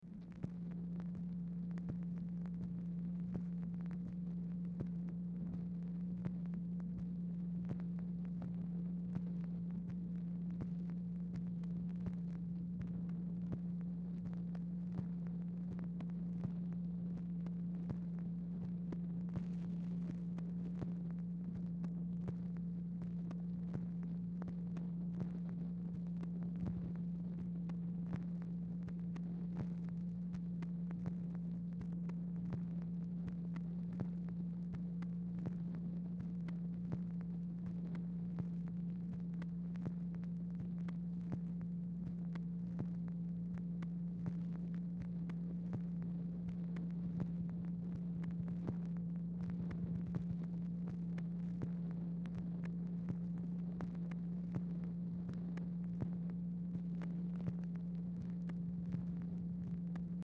Telephone conversation # 4434, sound recording, MACHINE NOISE, 7/30/1964, time unknown | Discover LBJ
Format Dictation belt
Specific Item Type Telephone conversation